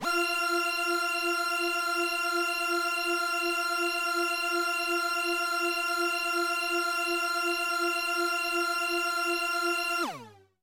描述：通过Modular Sample从模拟合成器采样的单音。
Tag: F6 MIDI音符-90 罗兰-HS-80 合成器 单票据 多重采样